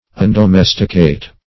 Search Result for " undomesticate" : The Collaborative International Dictionary of English v.0.48: Undomesticate \Un`do*mes"ti*cate\, v. t. [1st pref. un- + domesticate.]
undomesticate.mp3